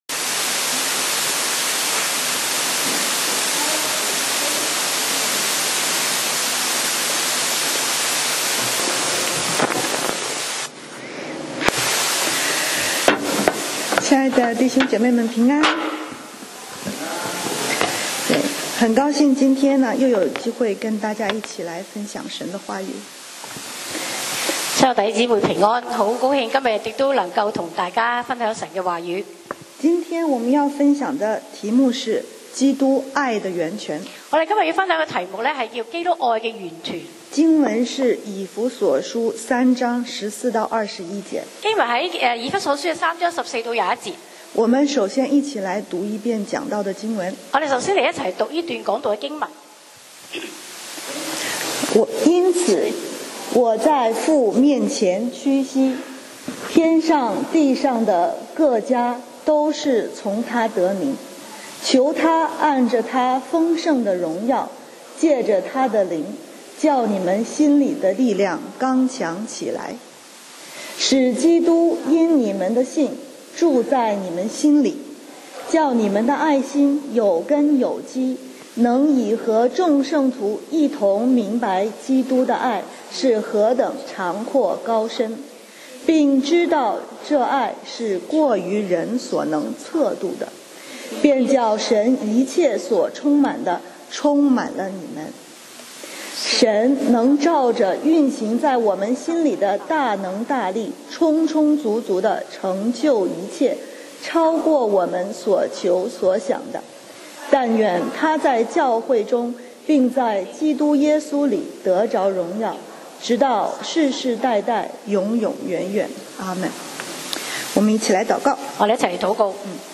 講道 Sermon 題目 Topic：基督，爱的源泉” 經文 Verses：以弗所书3：14-21 14因此，我在父面前屈膝，15天上地上的各（或作全）家，都是从他得名 16求他按着他丰盛的荣耀，藉着他的灵，叫你们心里的力量刚强起来，17使基督因你们的信，住在你们心里，叫你们的爱心，有根有基，18能以和众圣徒一同明白基督的爱，是何等长阔高深，19并知道这爱是过于人所能测度的，便叫神一切所充满的，充满了你们。